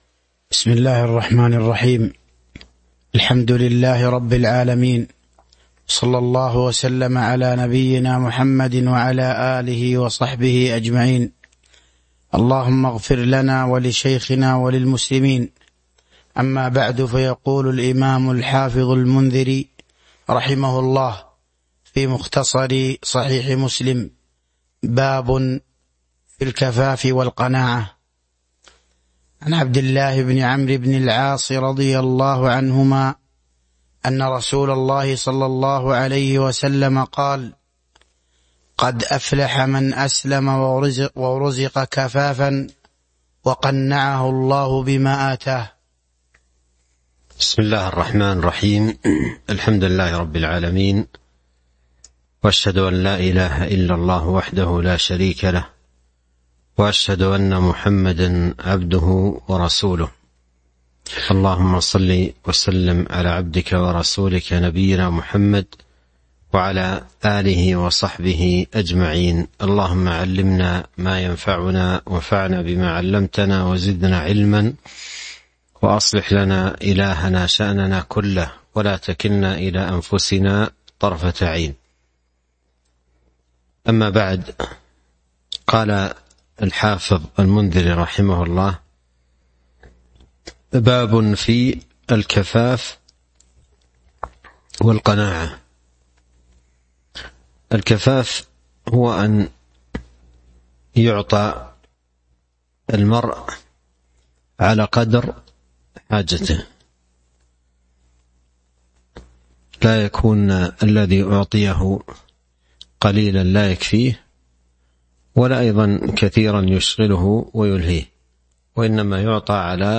تاريخ النشر ٢٥ رجب ١٤٤٢ هـ المكان: المسجد النبوي الشيخ